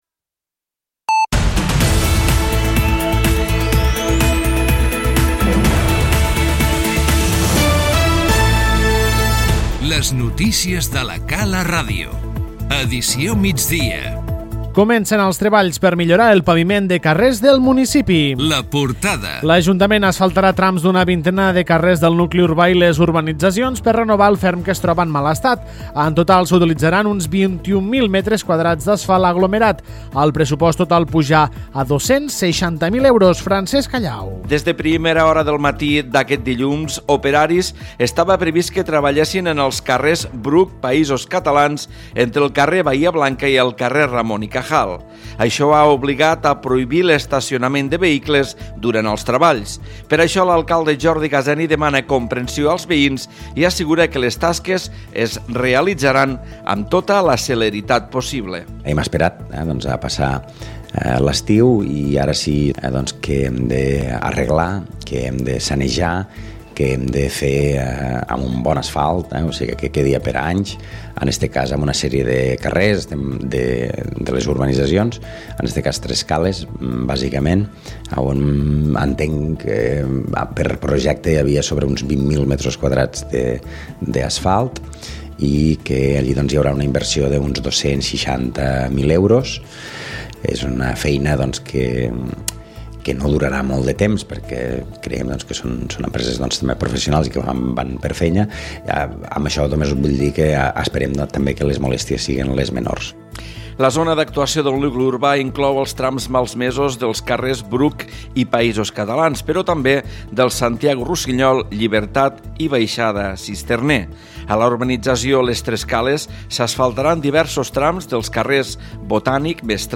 Les notícies migdia 11/12/2017